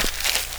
Index of /90_sSampleCDs/AKAI S6000 CD-ROM - Volume 6/Human/FOOTSTEPS_2
GRASS   3.WAV